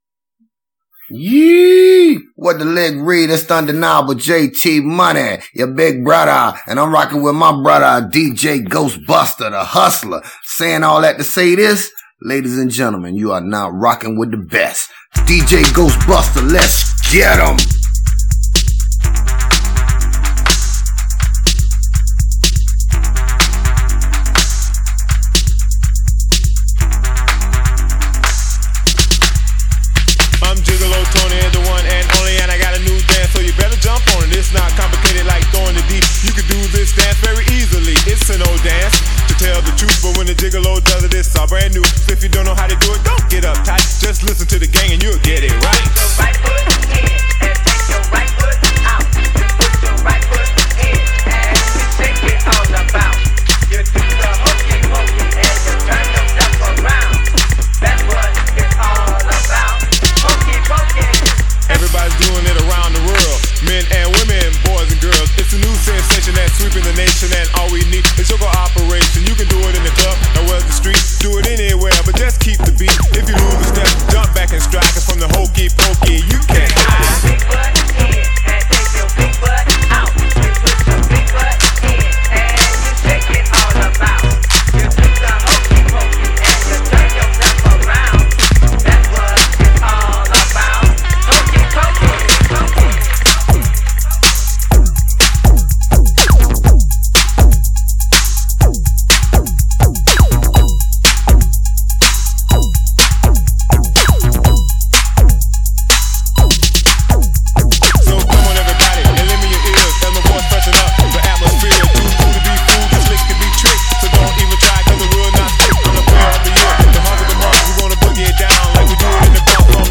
DJ Mixes
Blazing Old School Bass Classics From Da 80's & 90's